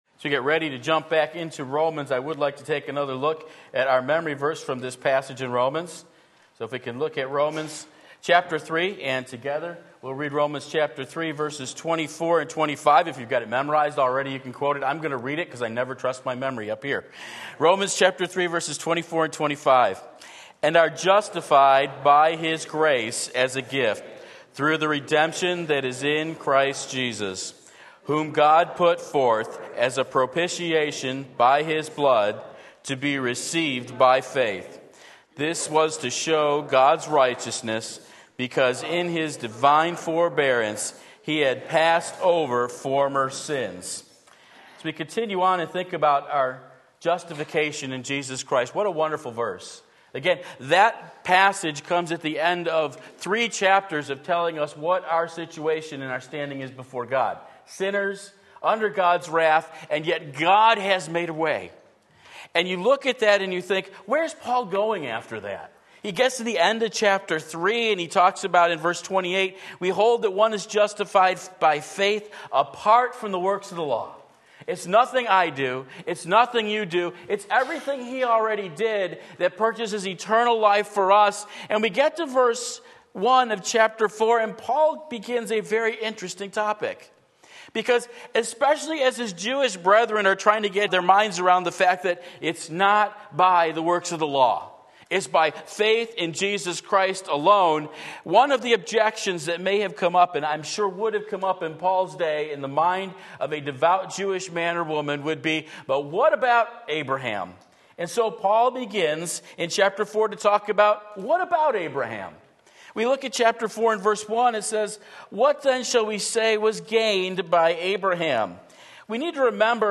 Sermon Link
Justified by Faith Romans 4:1-8 Sunday Morning Service